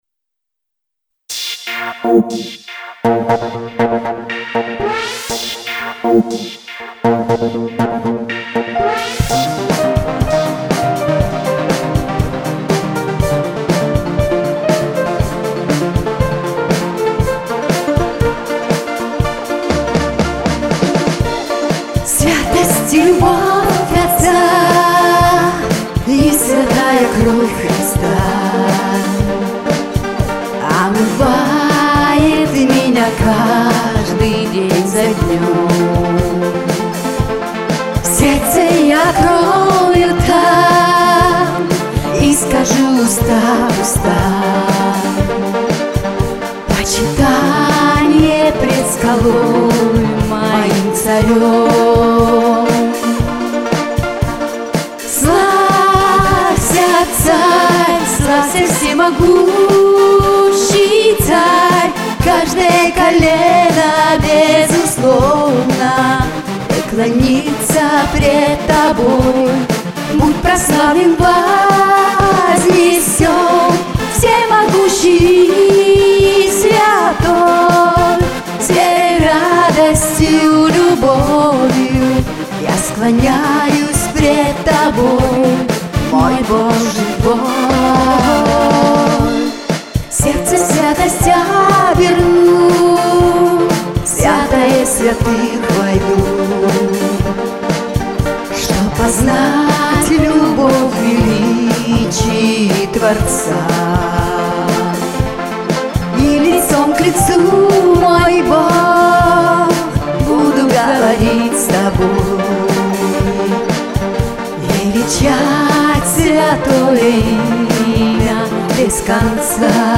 Христианская песня